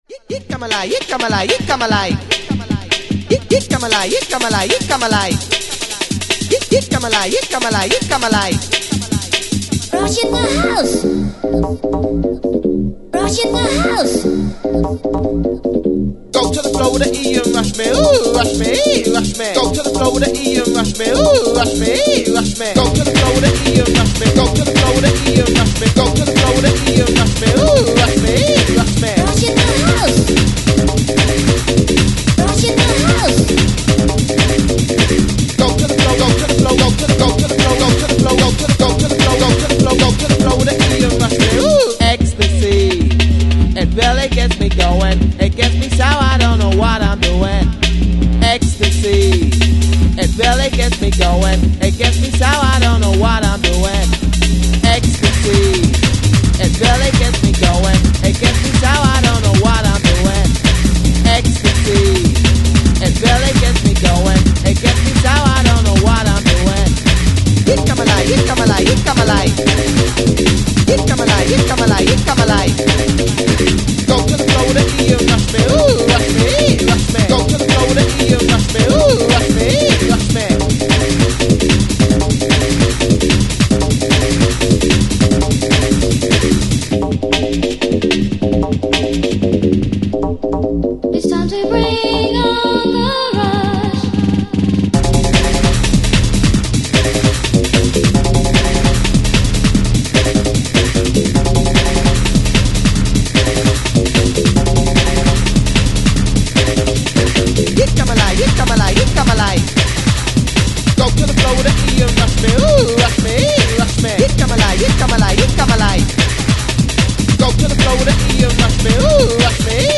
house, hardcore & techno